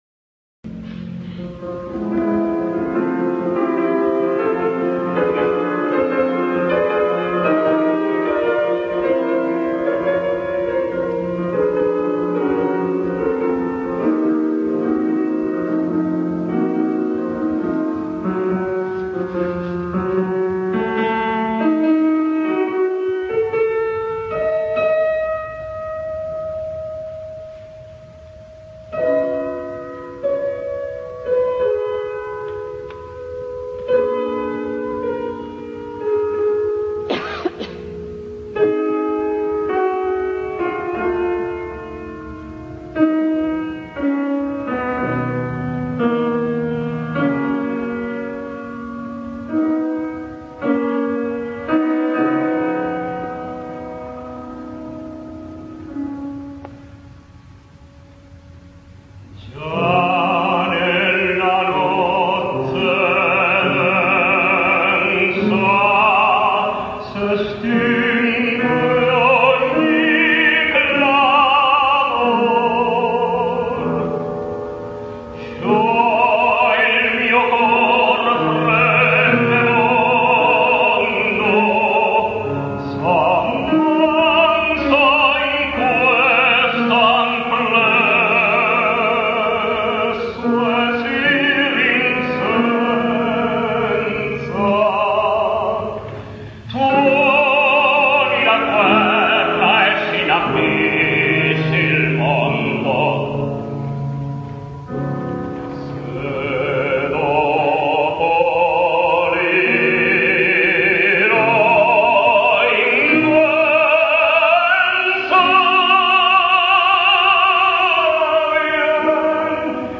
Argentinean tenor.